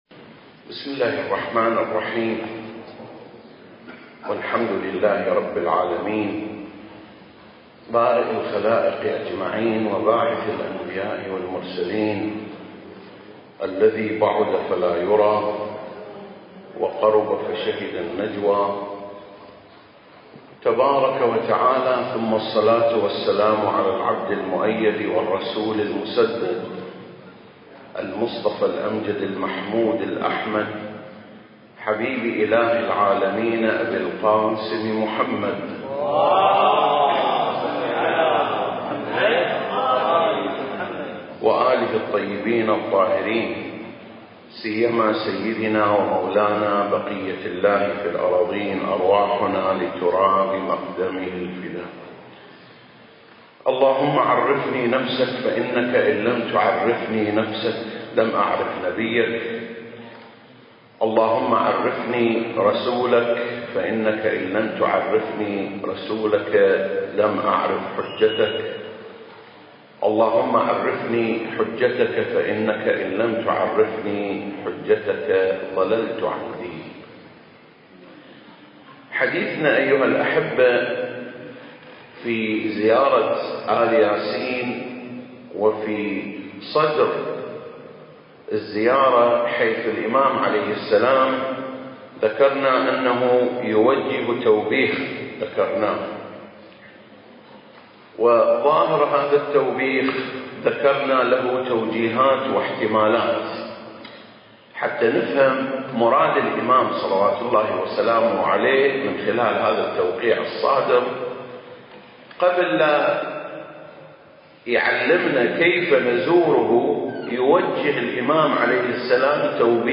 سلسلة: شرح زيارة آل ياسين (16) - قصة التوبيخ (4) المكان: مسجد مقامس - الكويت التاريخ: 2021